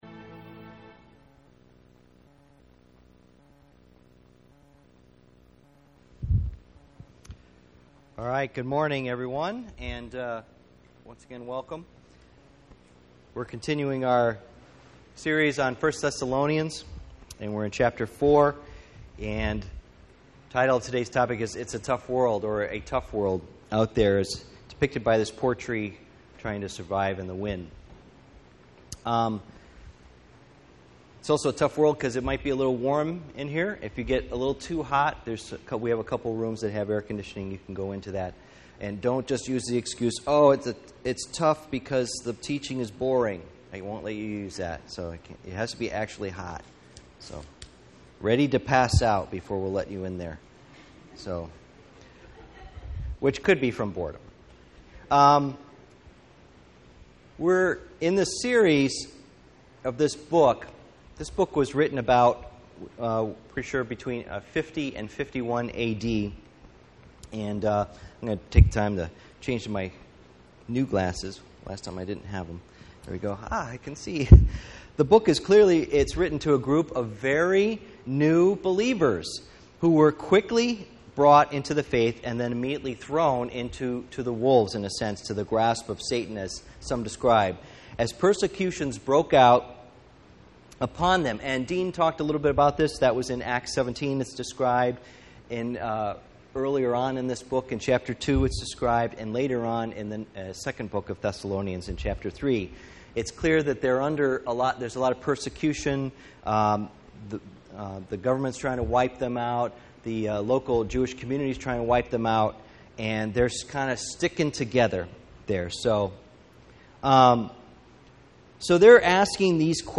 Passage: 1 Thessalonians 4:1-18 Service Type: Sunday Morning